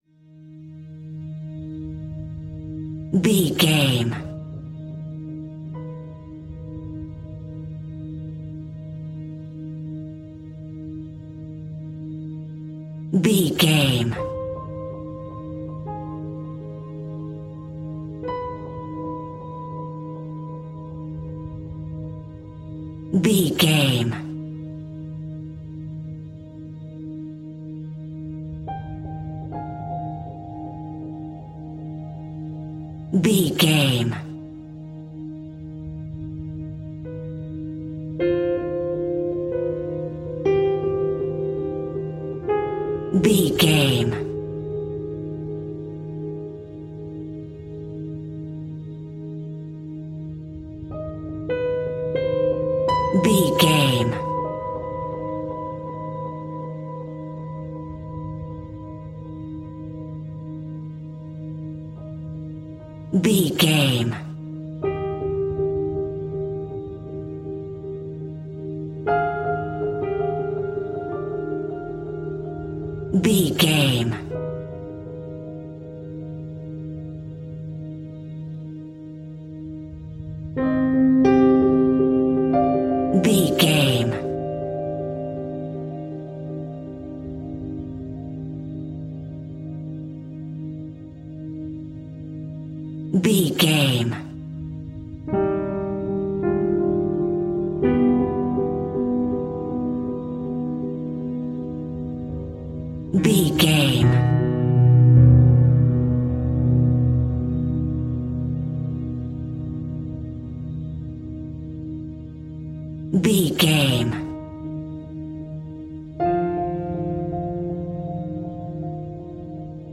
Piano Horror Drone.
Aeolian/Minor
E♭
Slow
ominous
haunting
eerie
strings